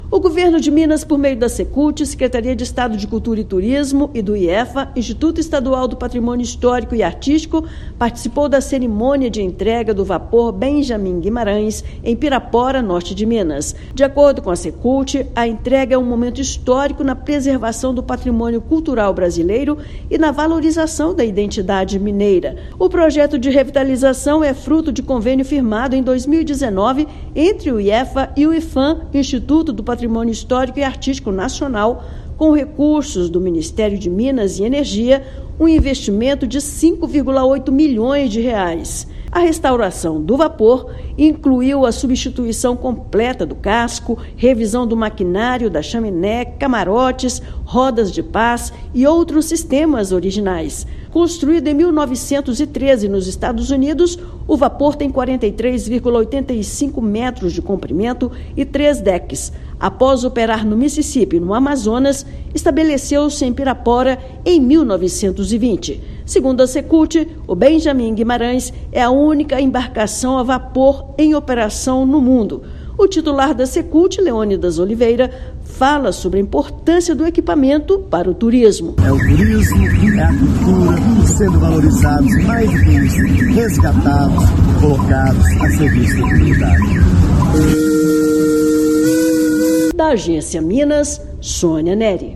[RÁDIO] Entrega do Vapor Benjamim Guimarães em Pirapora marca dia histórico para Minas Gerais
Cerimônia foi no último domingo (1/6) às margens do Rio São Francisco inaugura nova fase para o turismo e o patrimônio cultural no estado. Ouça matéria de rádio.